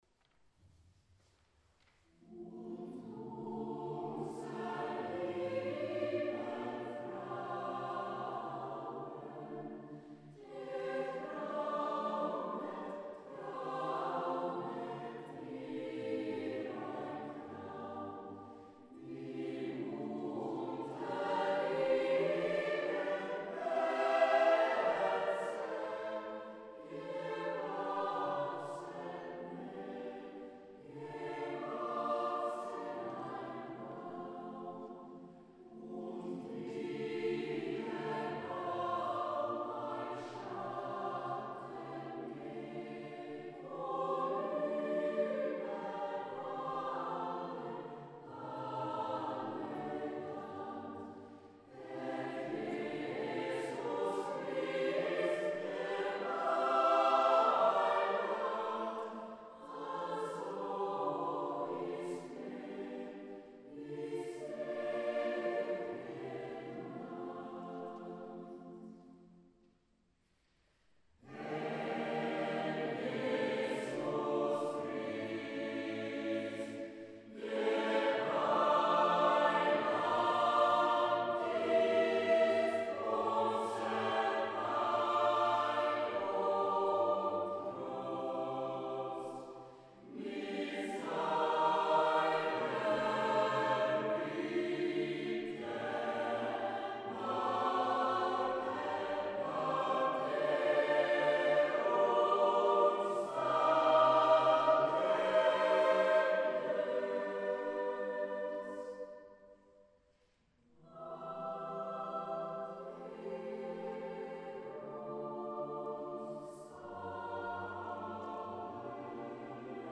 Julkonsert (2013)